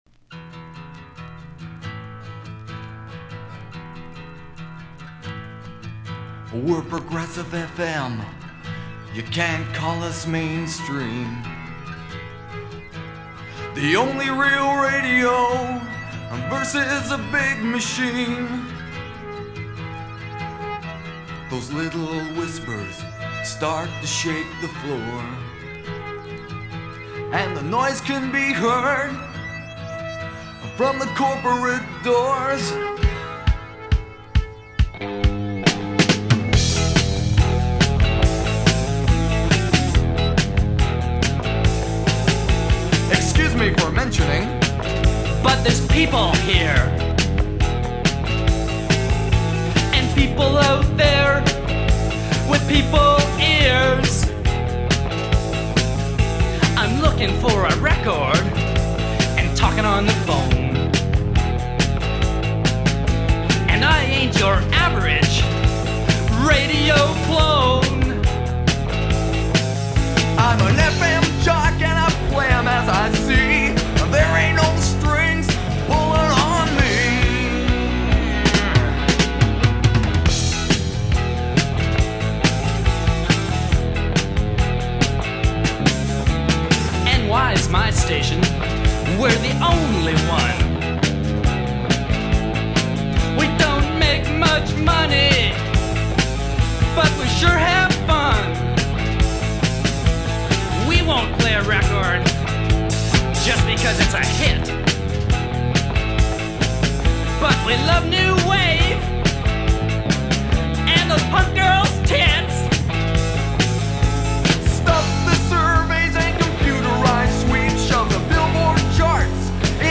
Song
Song recorded in 1979 by several of the staff